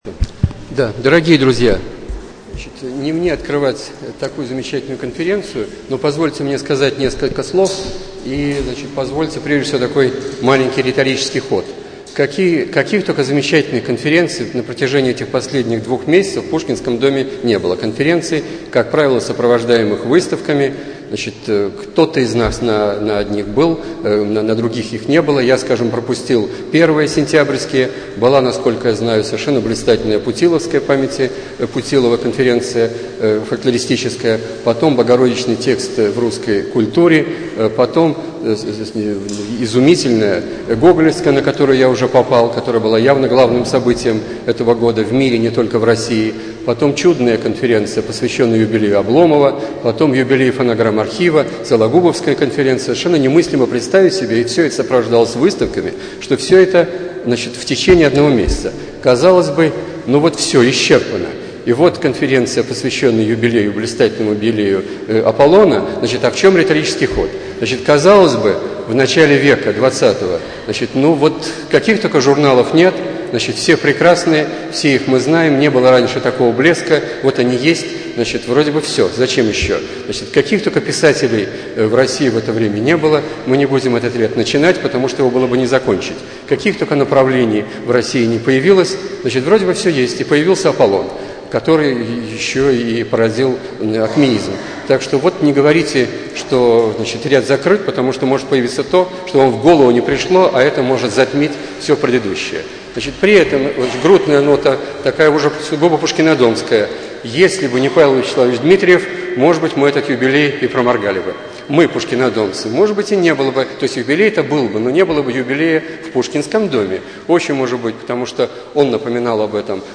Аудиозапись Заседания, посвященного 100-летию журнала «Аполлон» в Пушкинском доме 9 ноября 2009 года:
apollon_konferenciya_2009_1.mp3